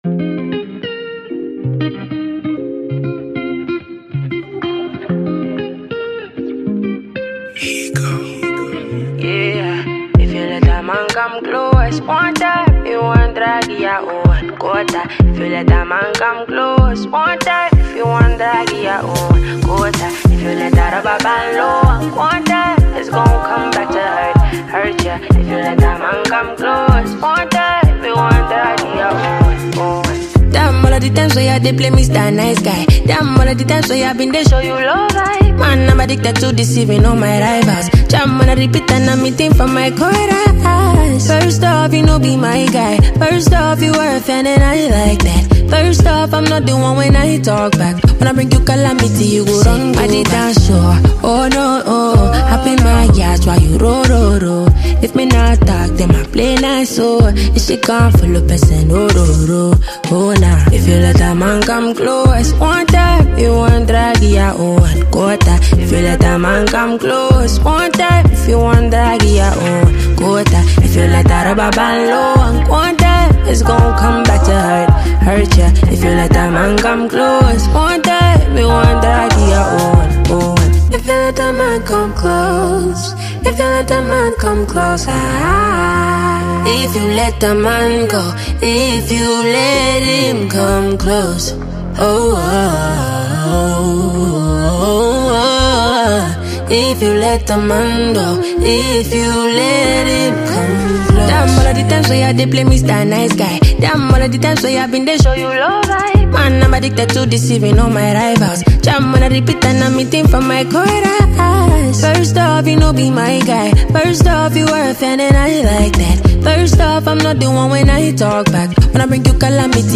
A-talented Nigerian female Singer & Songwriter